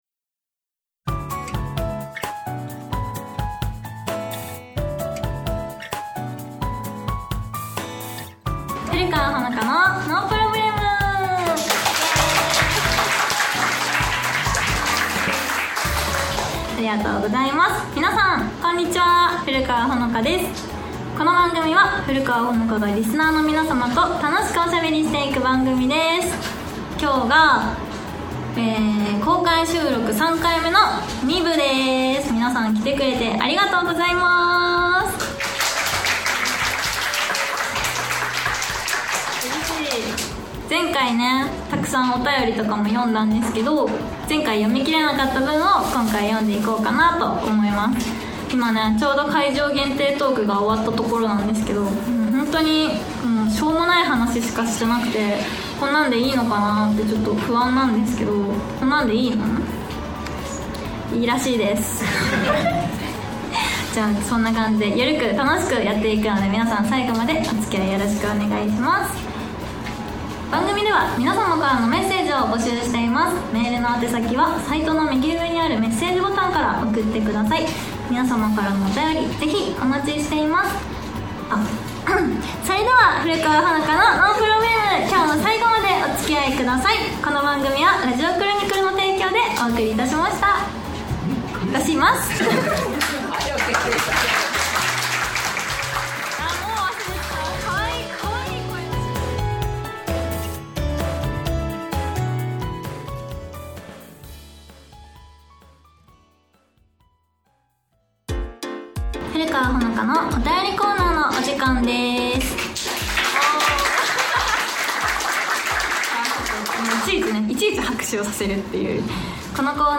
公開収録第2部をお届け！